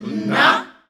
Index of /90_sSampleCDs/Voices_Of_Africa/ShortChantsShots&FX